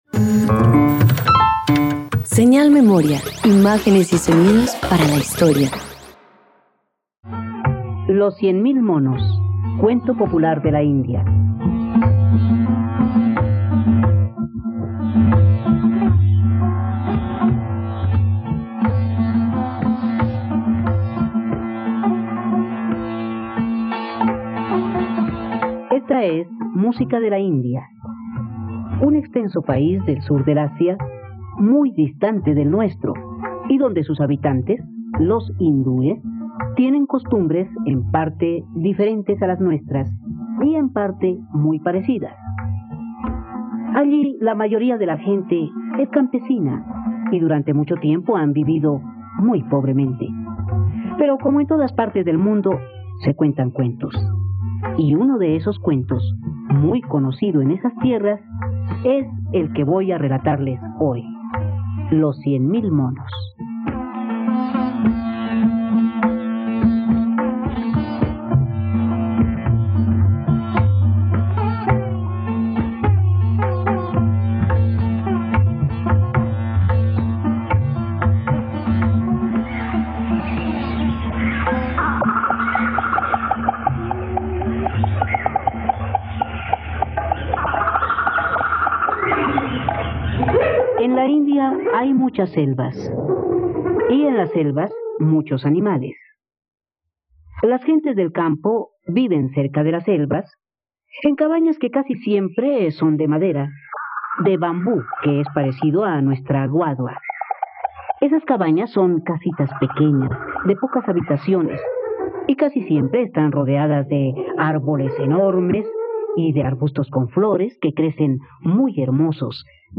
..Radioteatro. Disfruta el curioso cuento tradicional de la India, “Los cien mil monos”, en la plataforma de streaming de todos los colombianos: RTVCPlay.